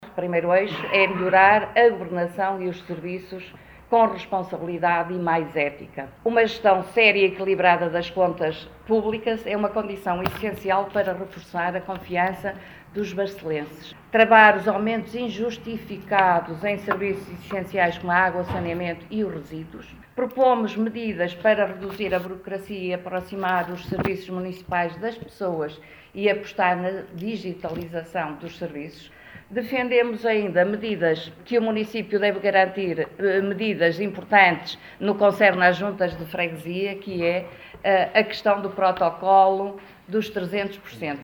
O PS de Barcelos apresentou, na tarde desta segunda-feira em conferência de imprensa, o compromisso eleitoral para os próximos 4 anos.